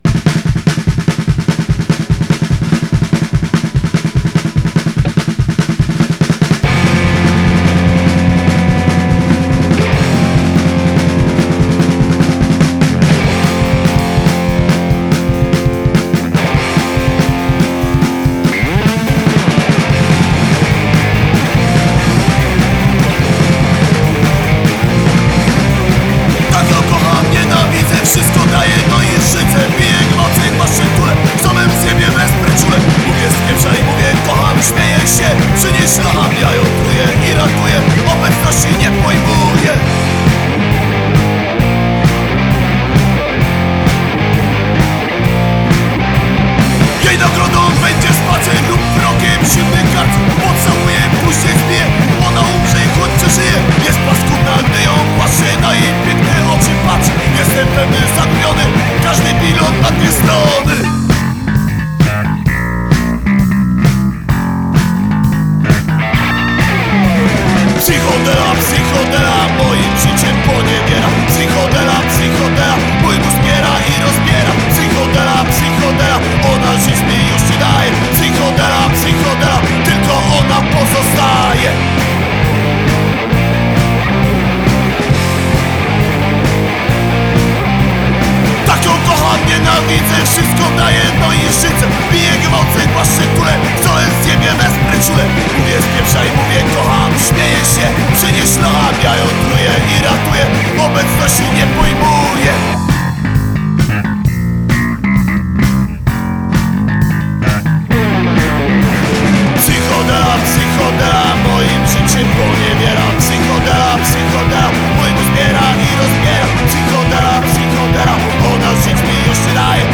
Gatunek: Street Punk